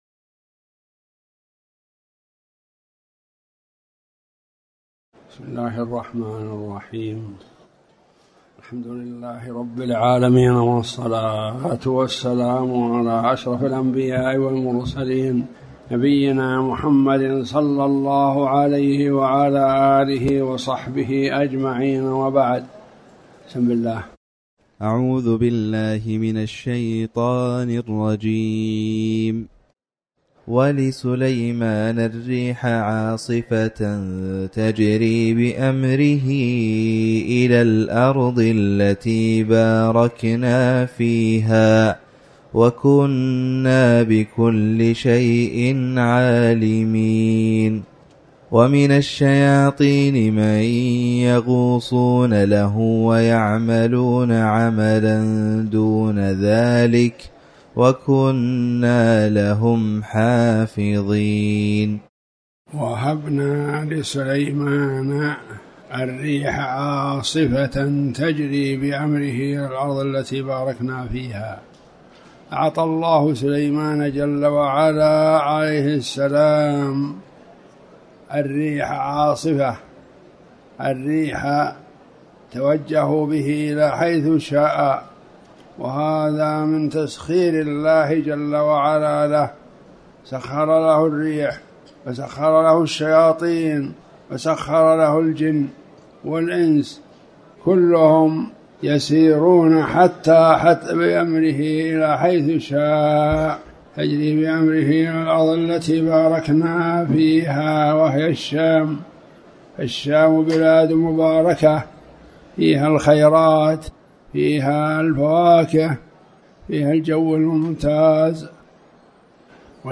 تاريخ النشر ٢٦ رجب ١٤٤٠ هـ المكان: المسجد الحرام الشيخ